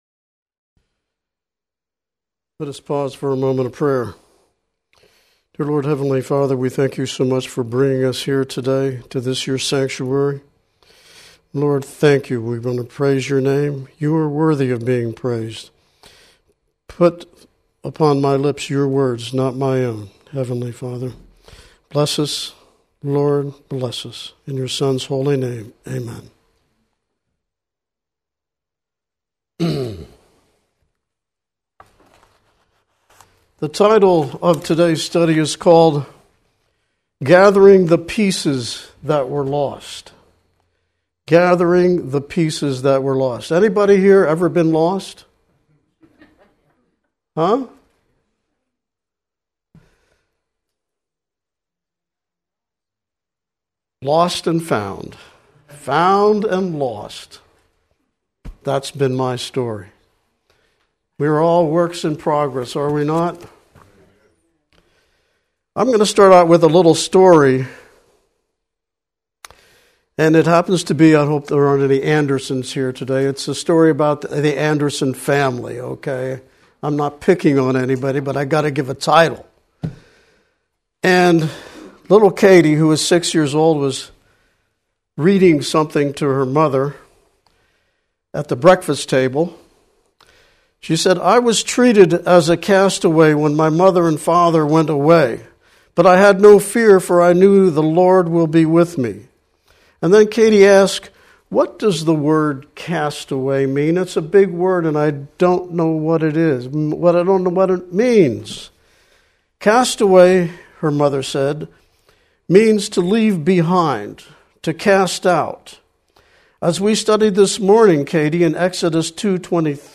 Recorded Church Services … continue reading 123 episodes # Religion # Mount # Pleasant # Texas # Adventist # Mount Pleasant Texas SDA # Christianity # SDA